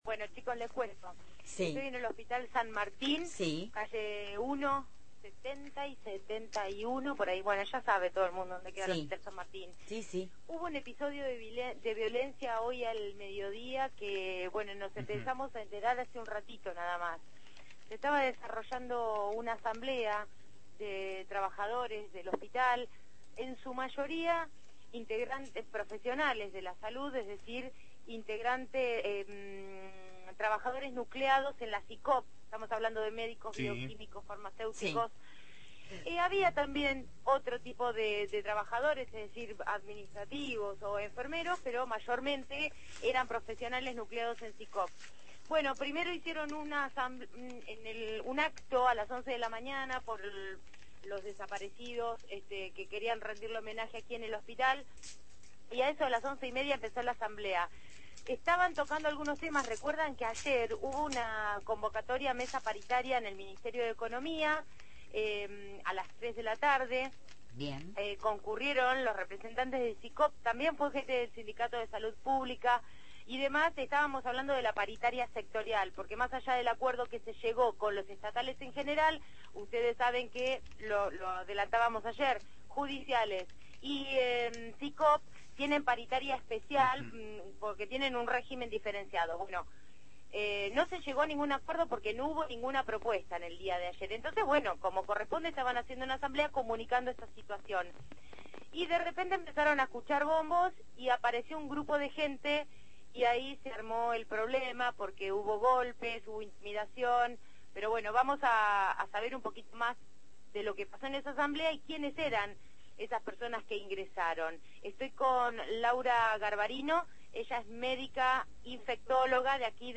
MÓVIL/Violencia y agresiones en asamblea Hospital San Martín